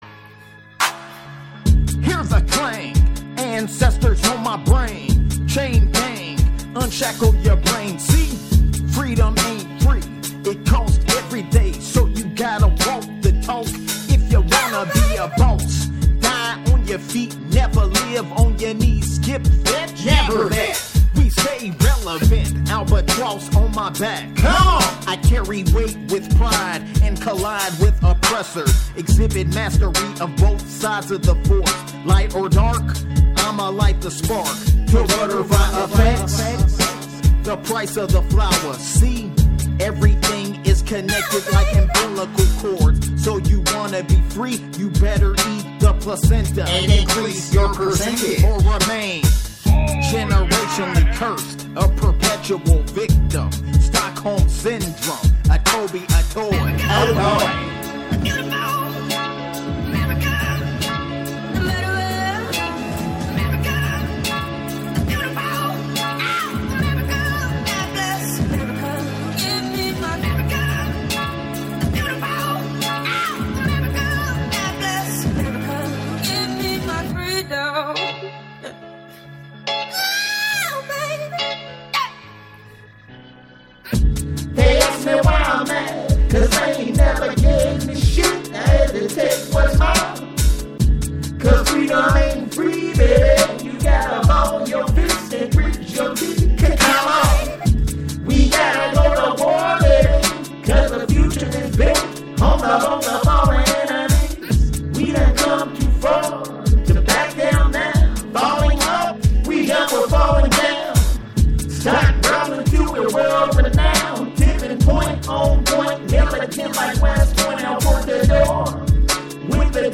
Freedom is a Spoken Word Piece Presented Over Beat, Which Speaks to the Generational Struggle to Aquire Wealth, Power & Prosperity in a World Full of Sycophants.